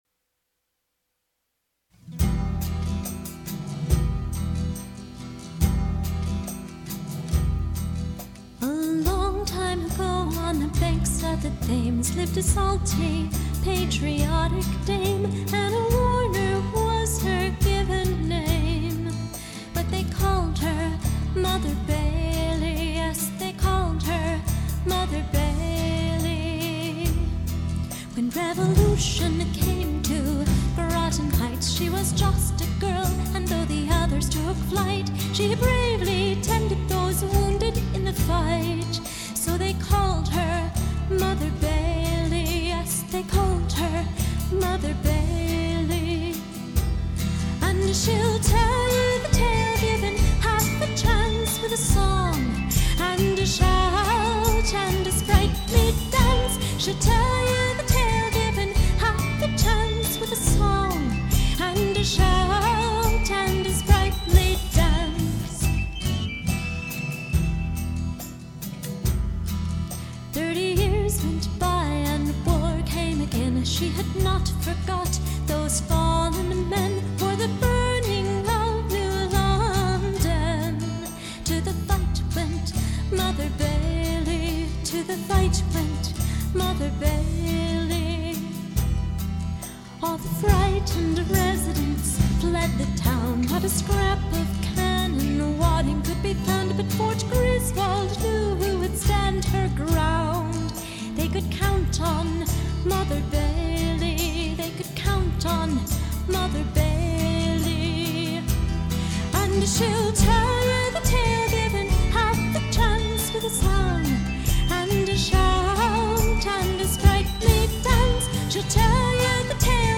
STYLE:  Piano Propelled FOLK/POP,
Contemporary and Neo-traditional Folk